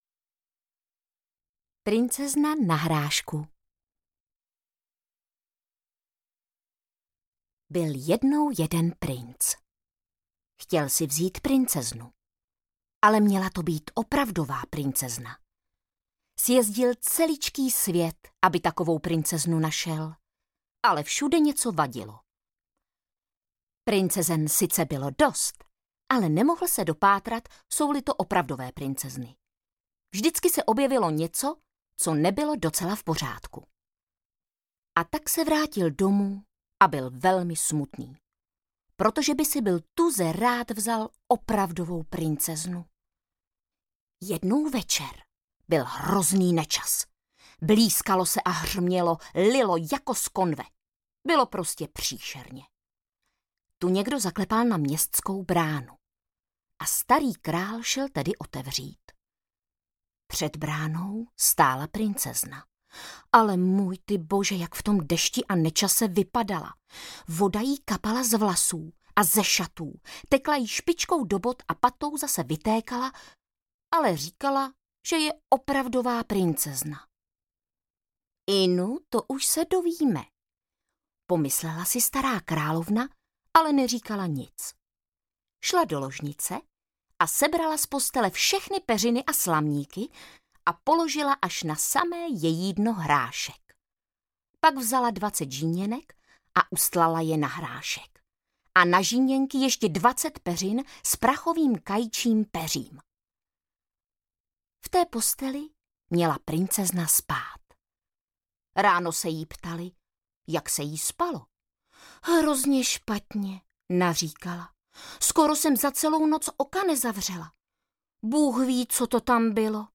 Princezna na hrášku audiokniha
Ukázka z knihy
• InterpretVáclav Knop